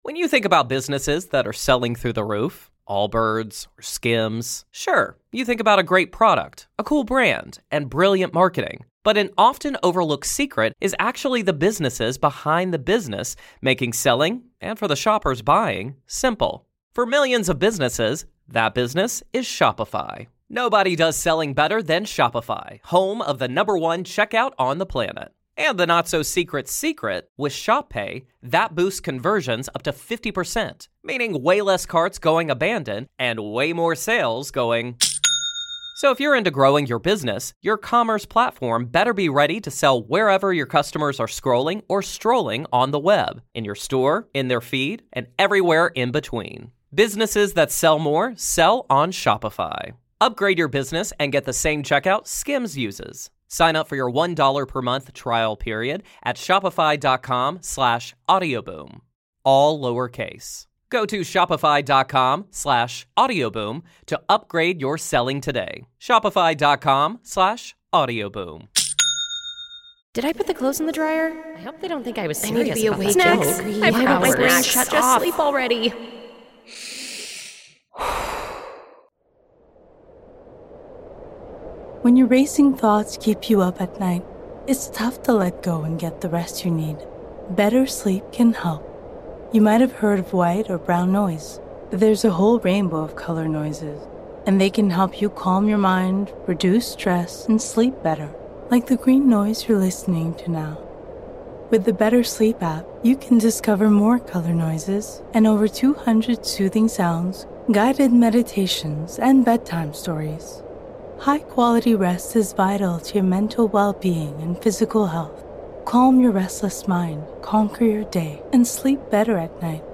with Manchester United fans after the Europa League final.